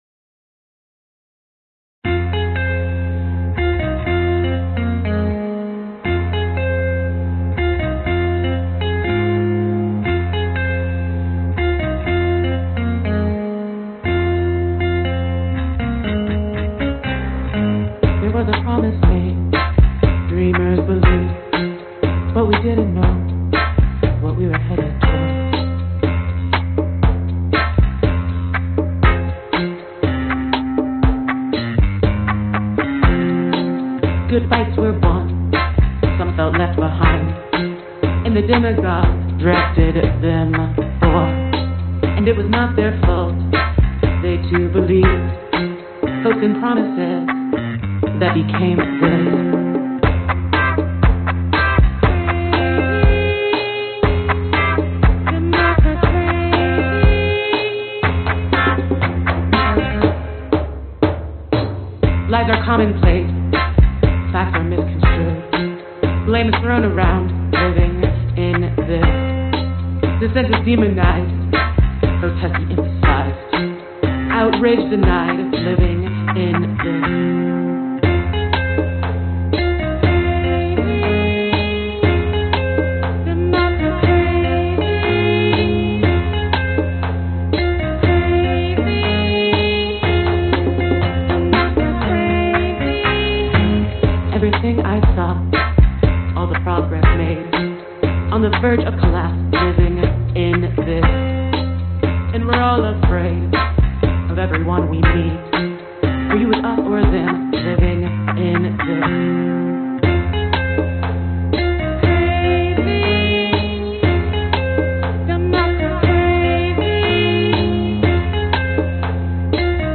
阿丹（清真寺召唤）早上5点，NR
描述：早上5点呼叫RASD（阿拉伯撒哈拉民主共和国）难民营的清真寺"27 de Febrero"。用AT822话筒在迷你光盘上录制立体声。用Soundtrack Pro降噪（高音量时可听到人工痕迹）。
Tag: 场记录 撒哈拉 鸟类 打电话 宣礼 早晨 清真寺